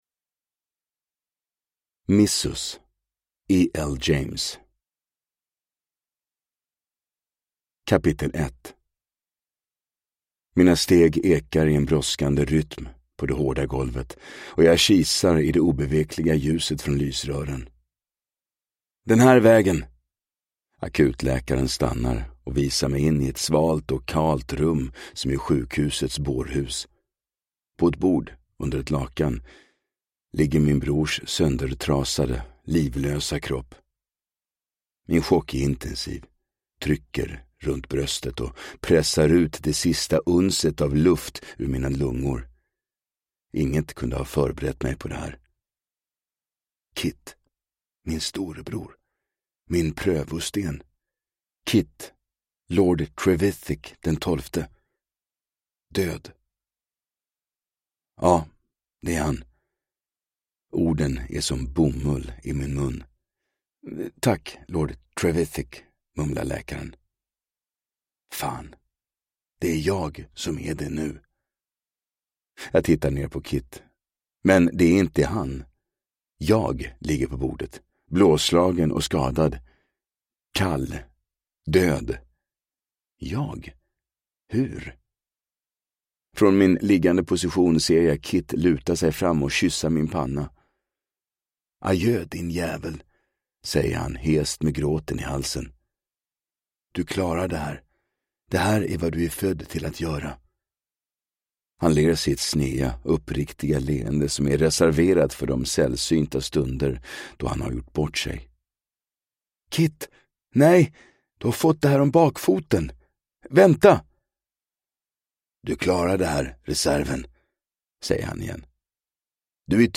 Missus – Ljudbok